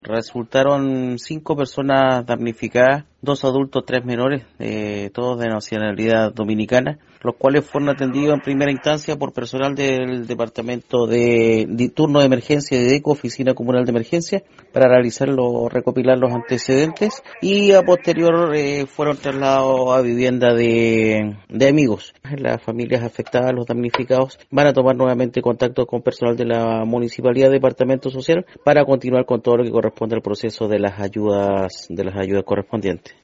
CUÑA-INCENDIO-ELN-.mp3